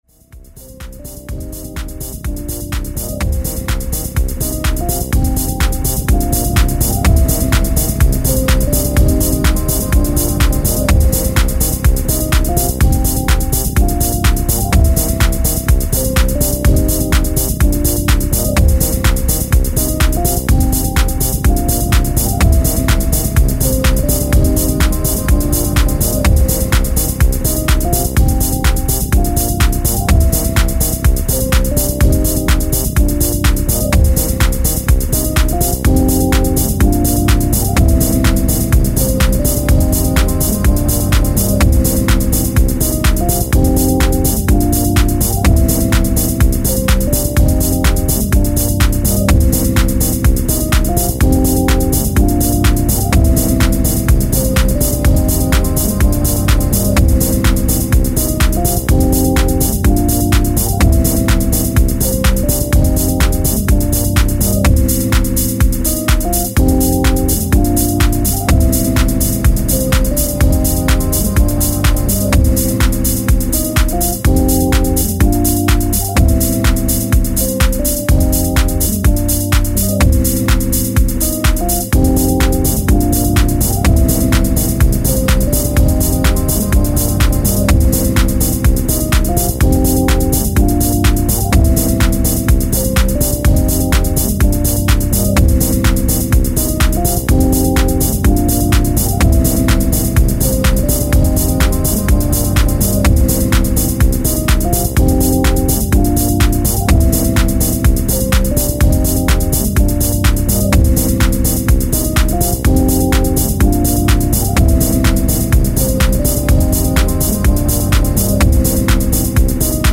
B2: Original Instrumental